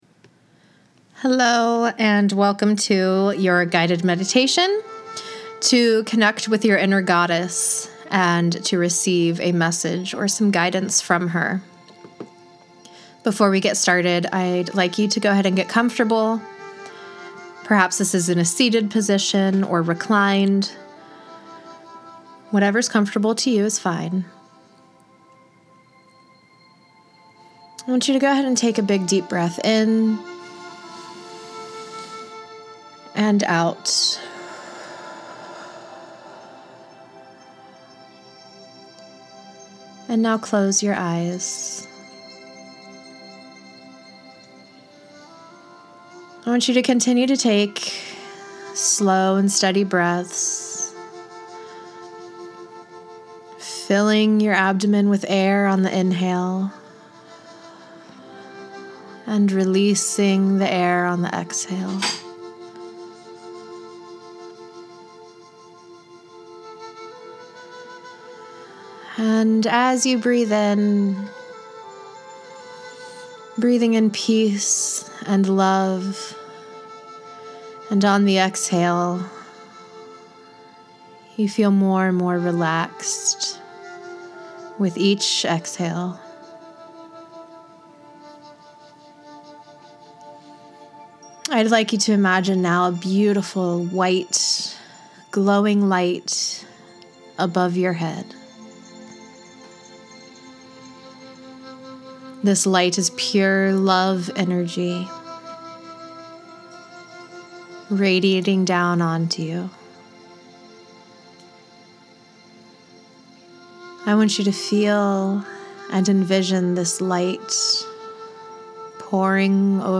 Guided Meditation to Connect to Your Inner Goddess